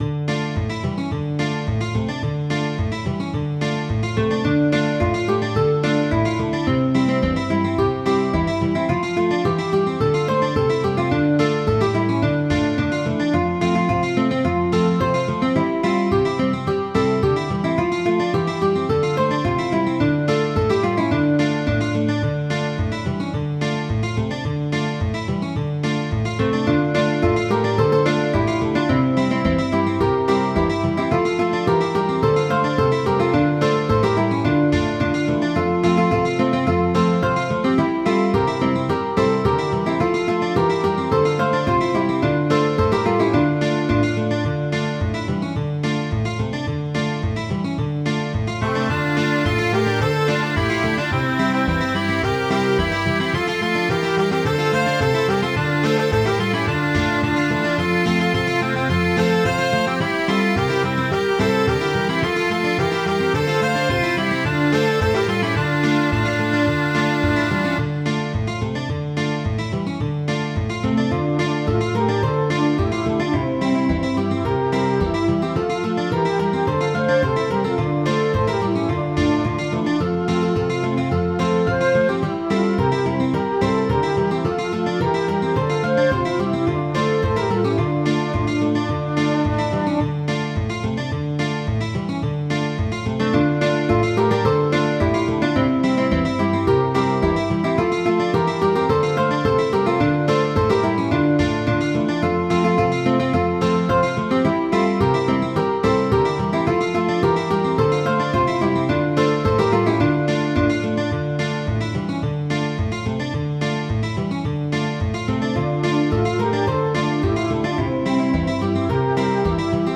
cope.mid.ogg